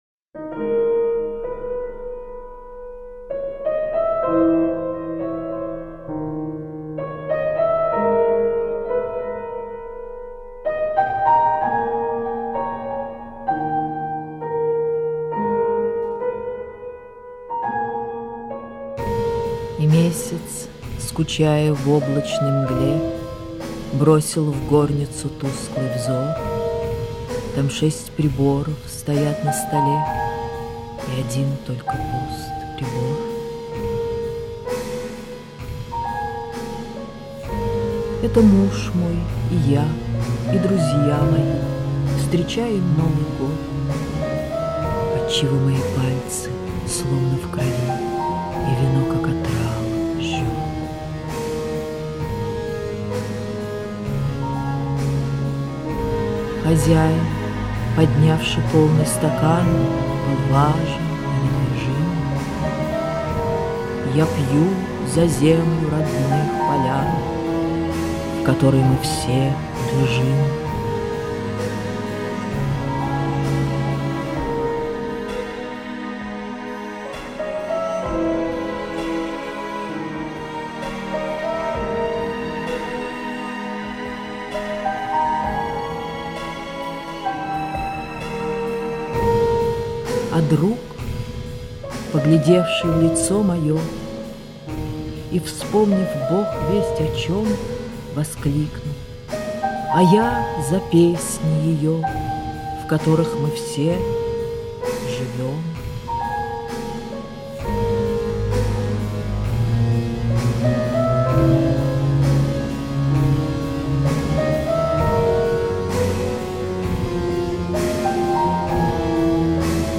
chitaet-alla-demidova-muz-zemfira-a-ahmatova-novogodnyaya-ballada